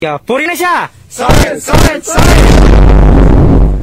Kategori: Suara viral